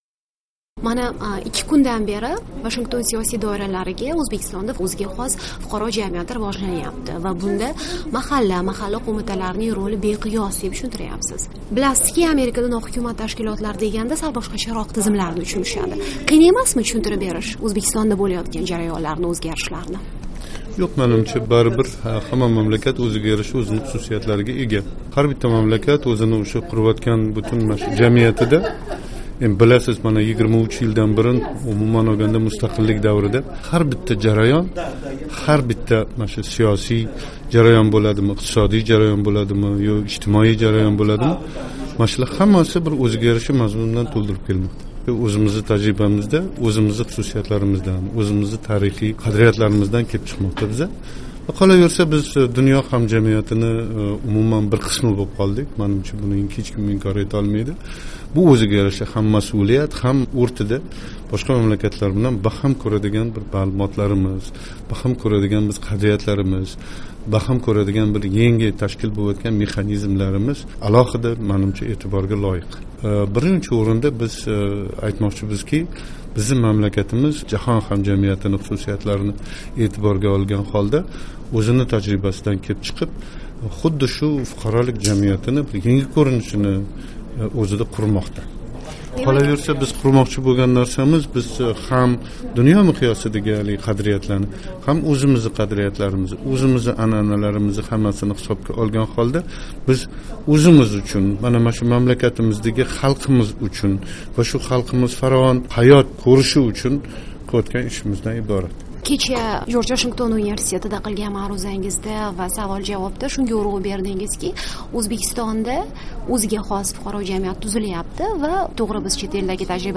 Davlat boshqaruvi akademiyasi rektori Abdujabbor Abduvohitov bilan suhbat